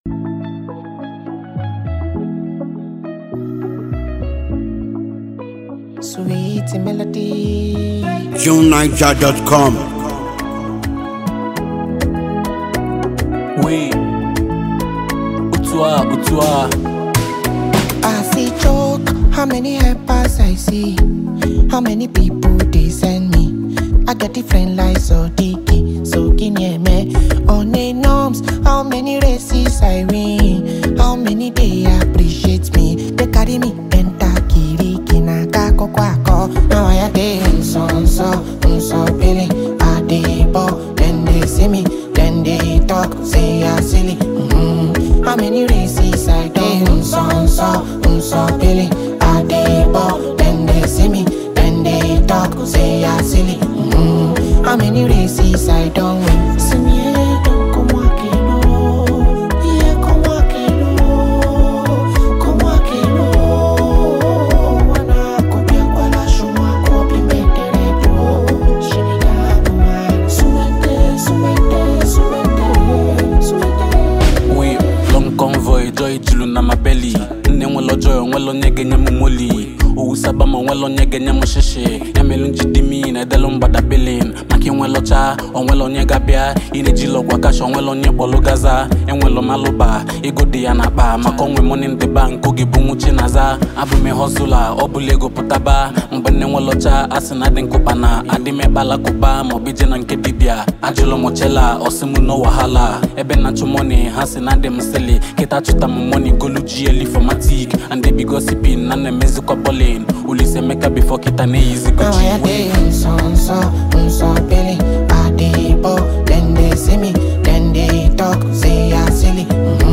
captivated the audience with her powerful vocals.